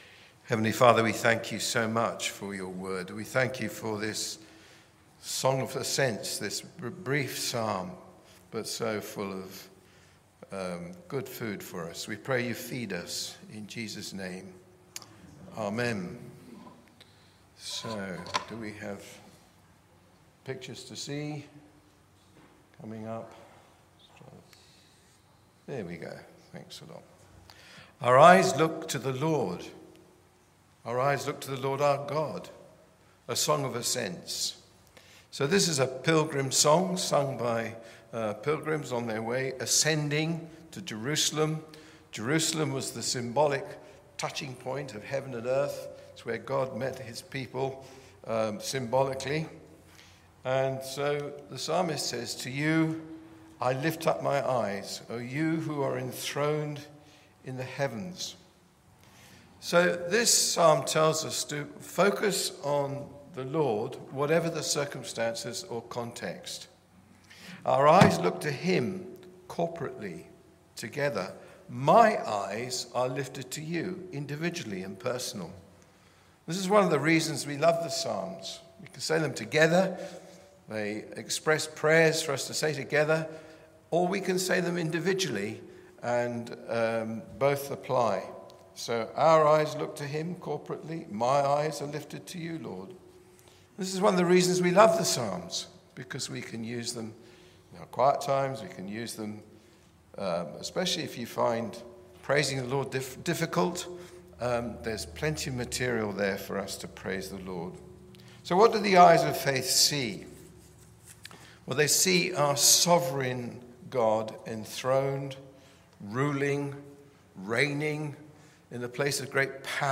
Listen to the sermon on Psalm 123 in our Psalms of Ascent series.